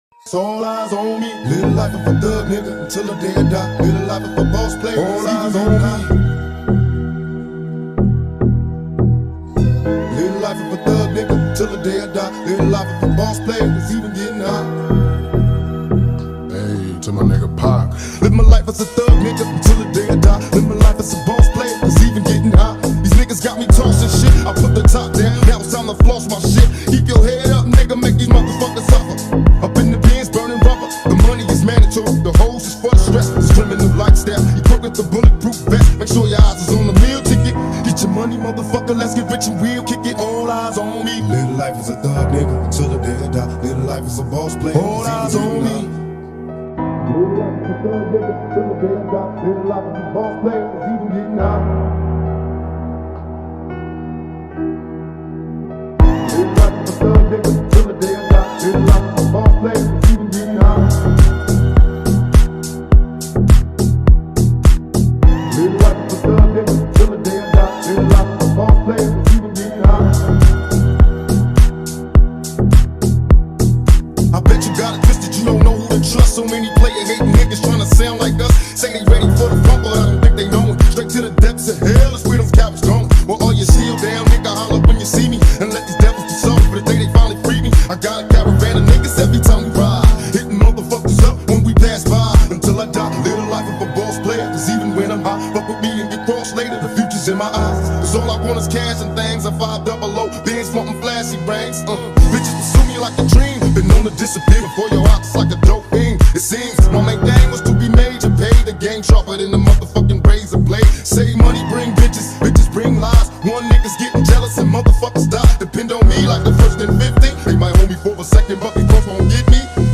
• Жанр: Rap, Hip-Hop, Electronic